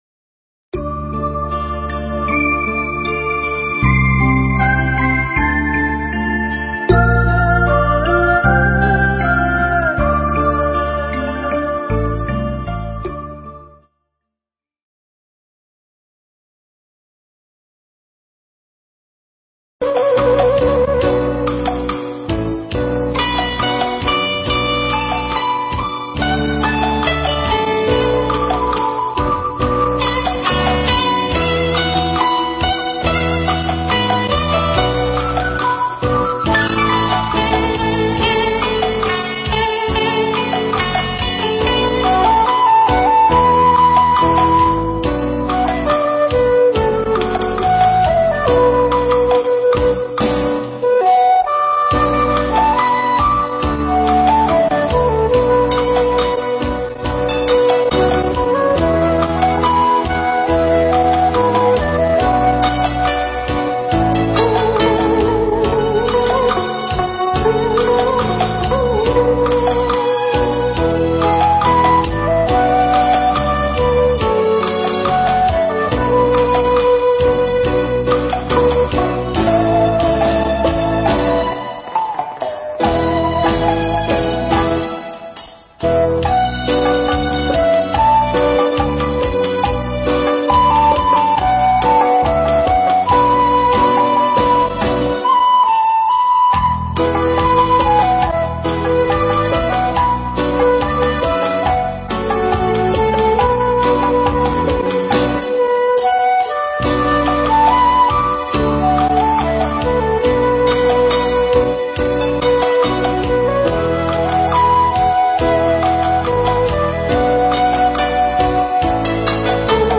Nghe mp3 Pháp Thoại Lý Nghiệp Báo Của Người Tu Phật – Hòa Thượng Thích Thanh Từ